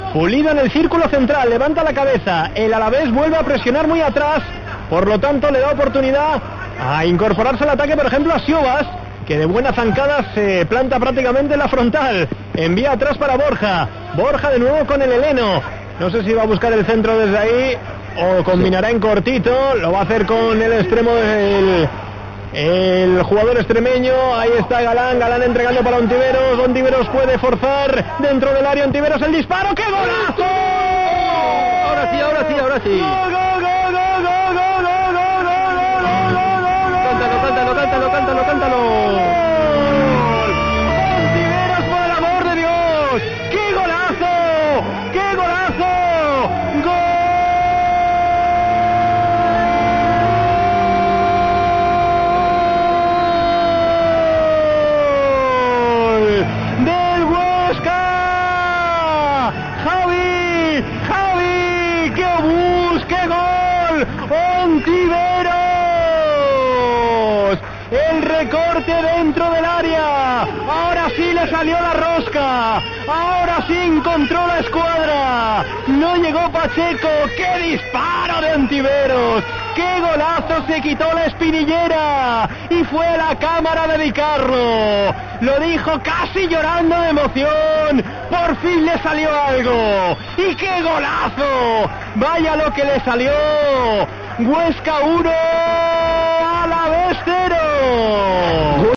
Narración Gol de Ontiveros / Huesca 1-0 Alavés